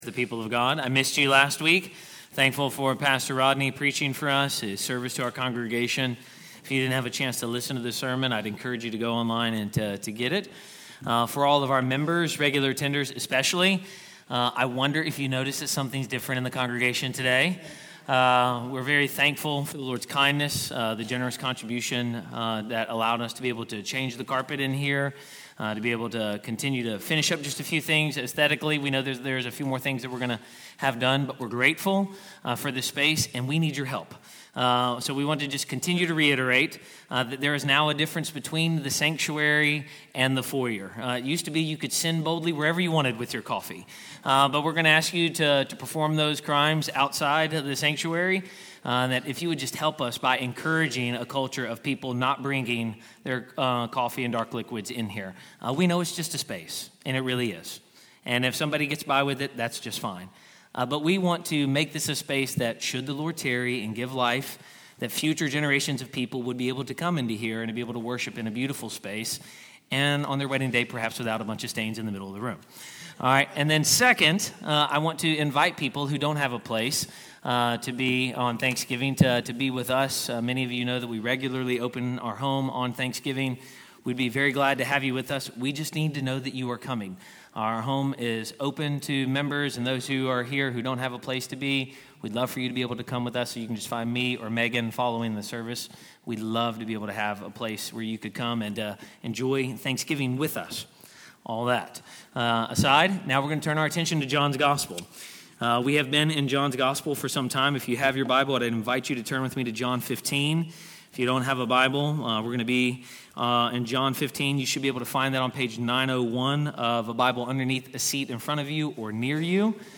Sermon-1124.mp3